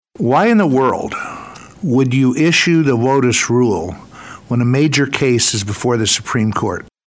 Environment Appropriations Chair Mike Simpson asked a question of to EPA chief Michael Regan.